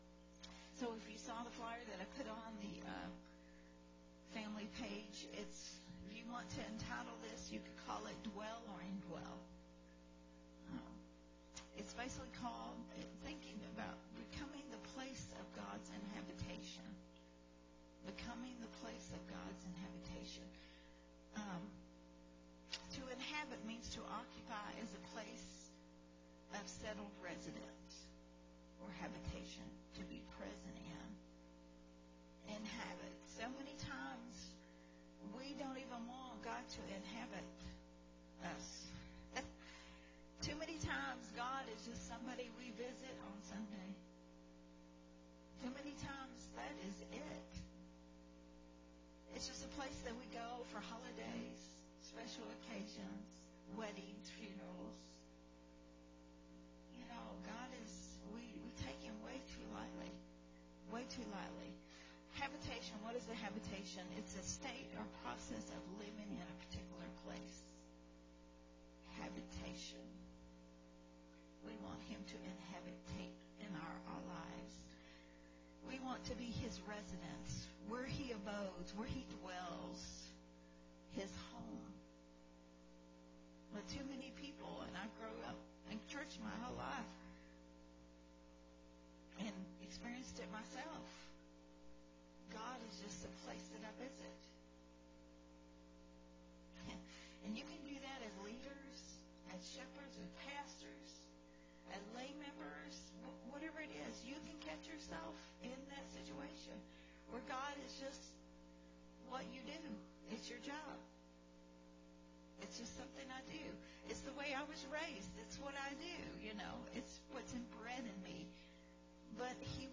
recorded at Unity Worship Center